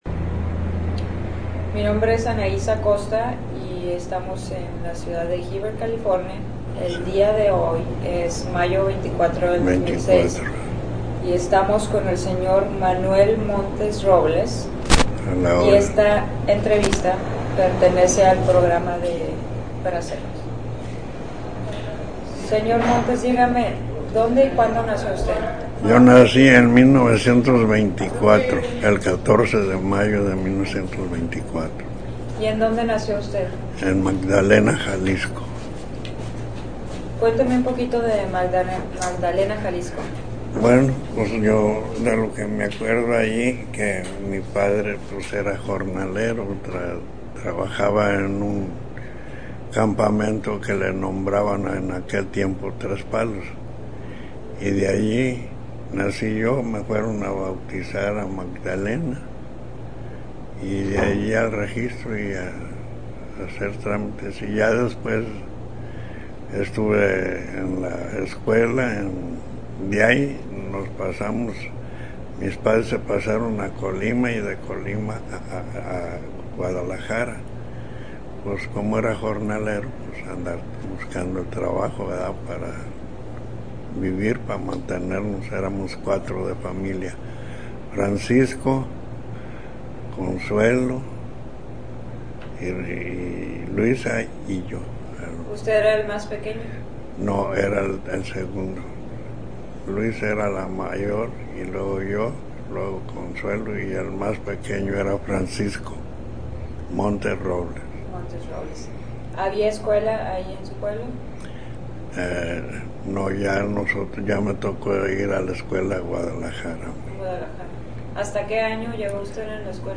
Location Heber, CA